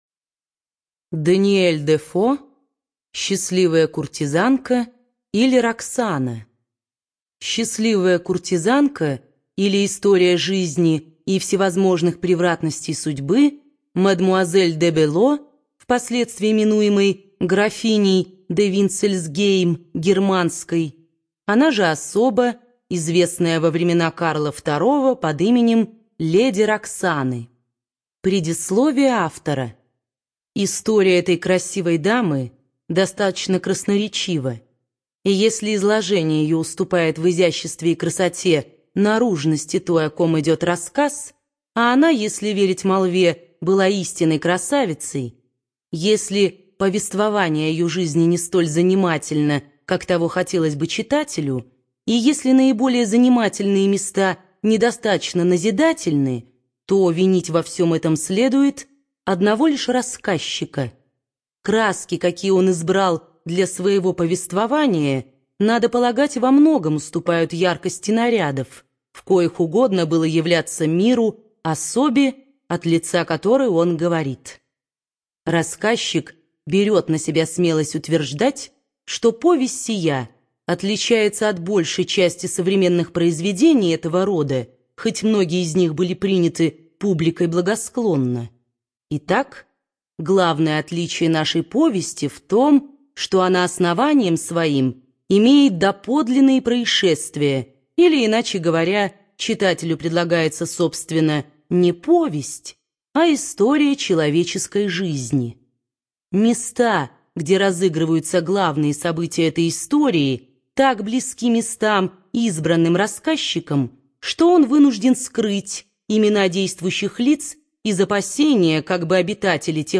Студия звукозаписиБиблиофоника